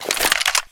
CSGO Awp Draw